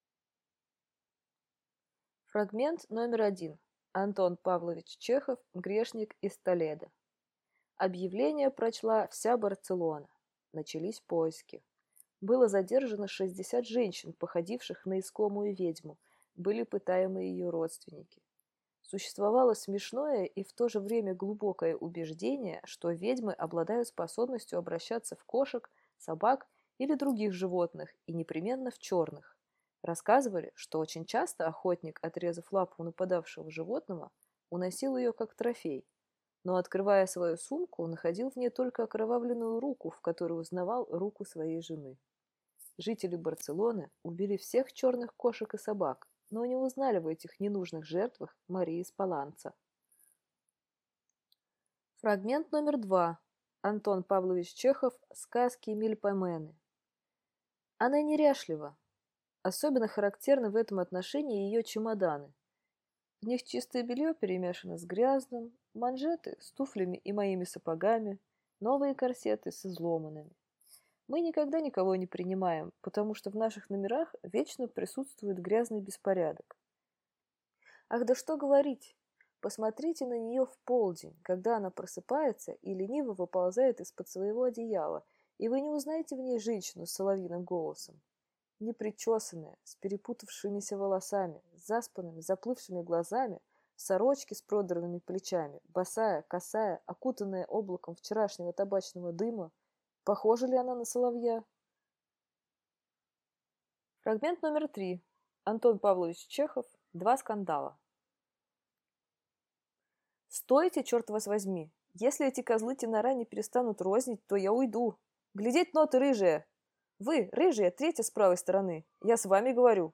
Пример звучания голоса
Жен, Аудиокнига/Молодой